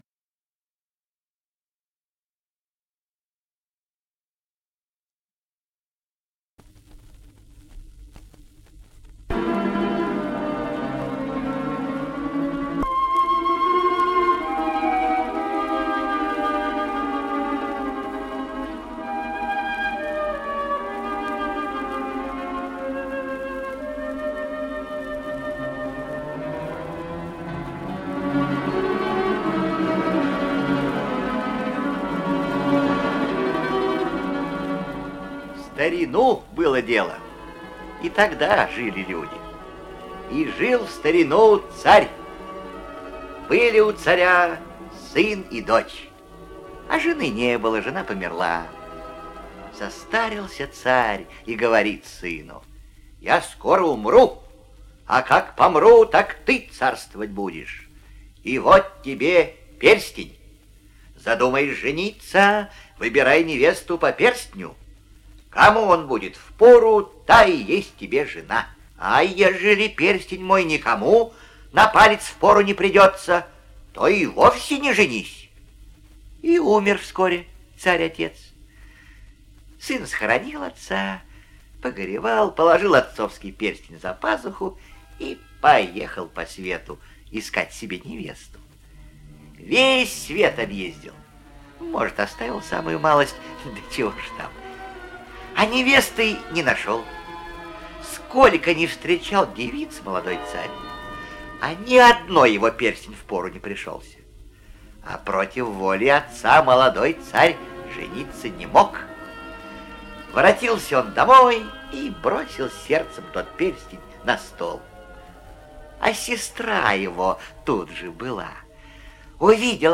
Чудесный мальчик - русская народная аудиосказка